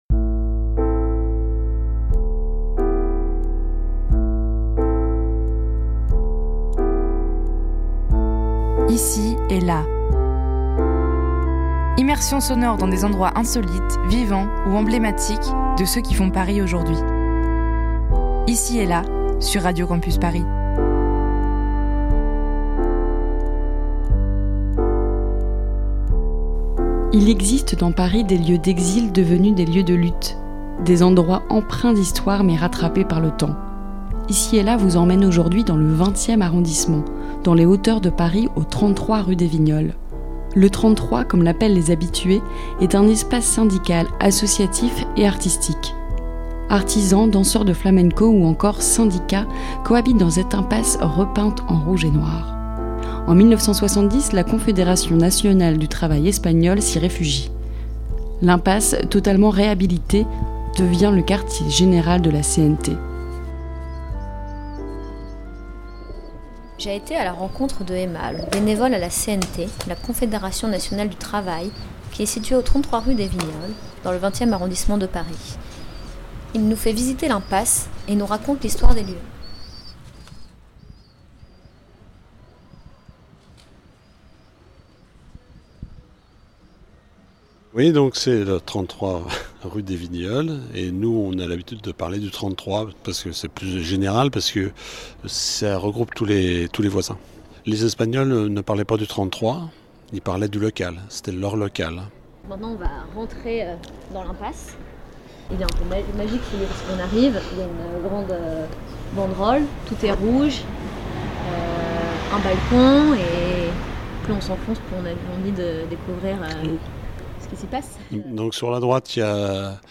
Un reportage diffusé pour la première fois dans La Porte A C ôté .